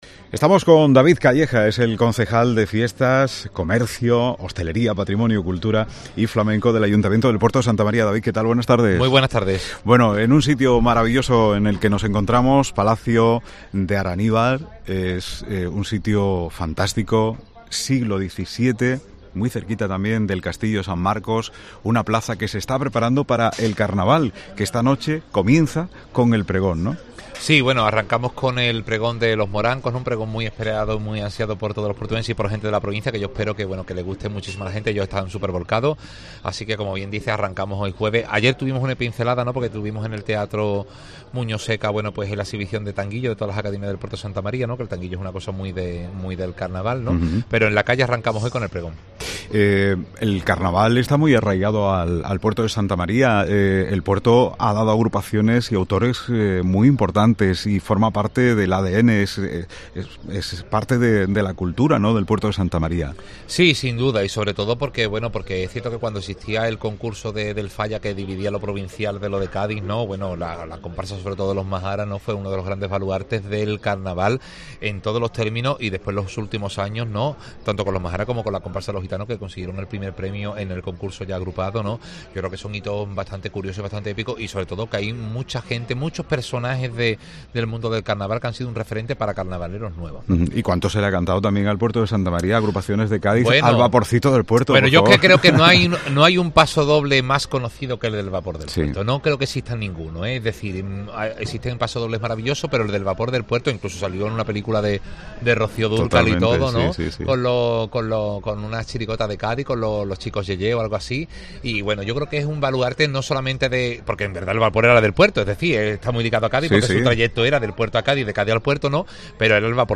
Hoy en Herrra en COPE Provincia de Cádiz hemos contado con el Concejal de Fiestas del Ayuntamiento de El Puerto de Santa María David Calleja, que ha contado la programación e importancia de El Carnaval para la ciudad que tendrá lugar del 19 al 28 de febrero.